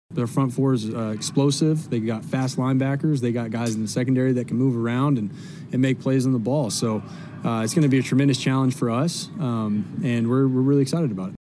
Raiders quarterback Jarrett Stidham says that Kansas City’s defense is strong at each level.